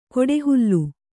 ♪ koḍehullu